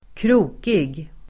Uttal: [²kr'o:kig]